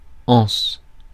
Ääntäminen
Synonyymit courbe crique golfe demi-ovale poignée oreille baie Ääntäminen France: IPA: [ɑ̃s] Haettu sana löytyi näillä lähdekielillä: ranska Käännös Substantiivit 1. asa {f} Suku: f .